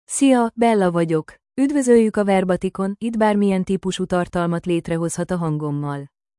BellaFemale Hungarian AI voice
Bella is a female AI voice for Hungarian (Hungary).
Voice sample
Listen to Bella's female Hungarian voice.
Female